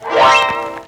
digiharp.wav